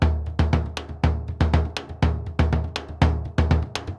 Bombo_Baion_120_2.wav